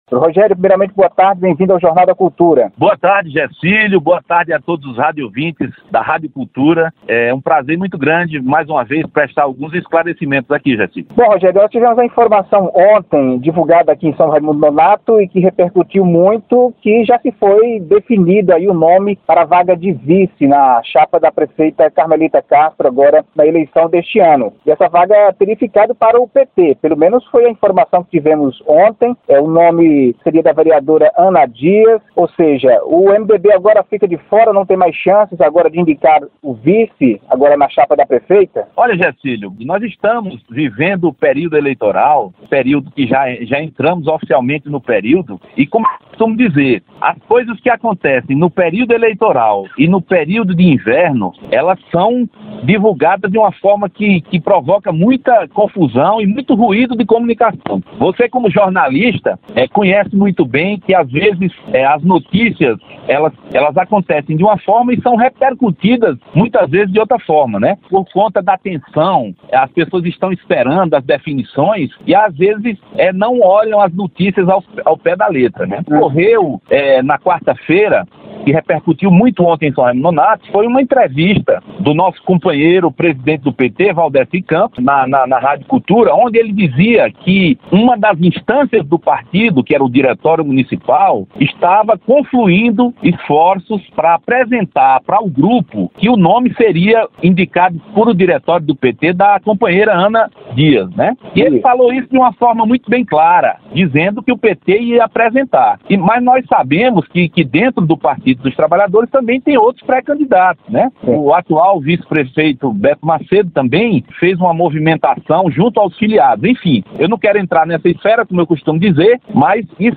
Em entrevista às Rádios Cultura FM e Serra da Capivara AM na sexta-feira (21)